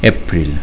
April